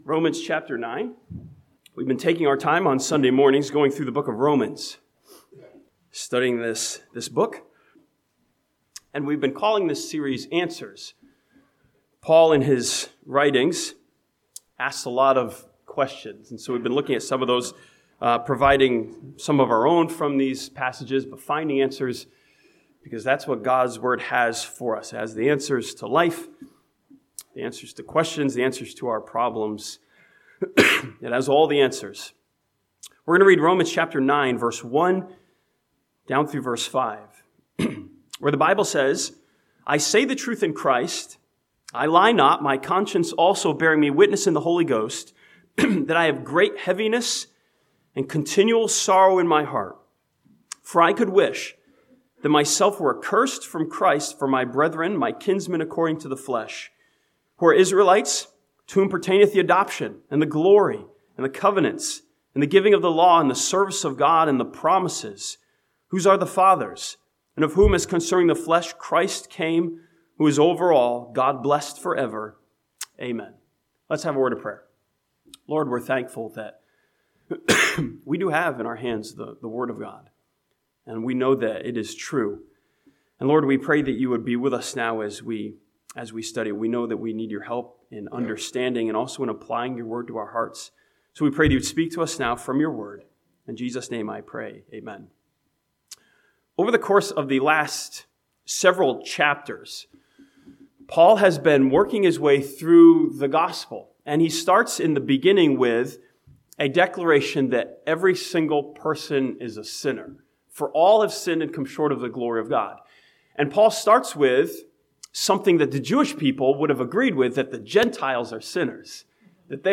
This sermon from Romans chapter 9 challenges us with a question of affection: "how do I love the world?"